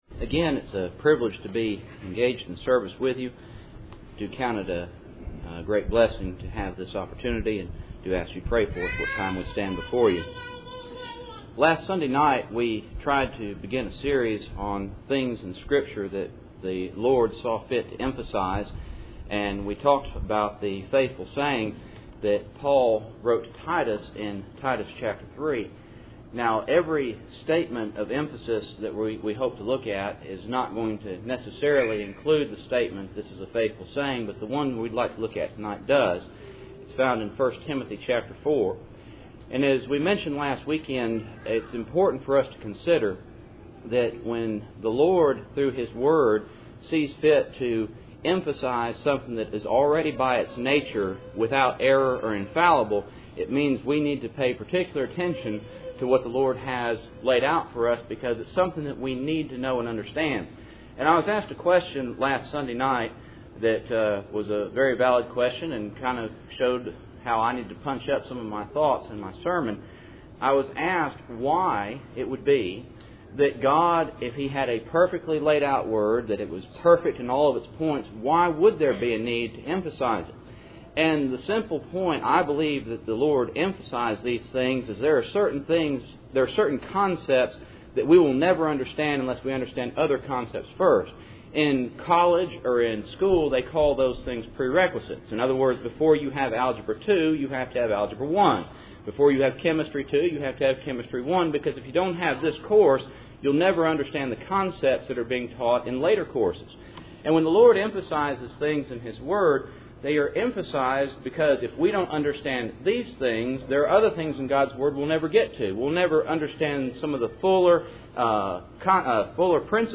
Passage: 1 Timothy 4:7-9 Service Type: Cool Springs PBC Sunday Evening